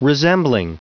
Prononciation du mot resembling en anglais (fichier audio)
Prononciation du mot : resembling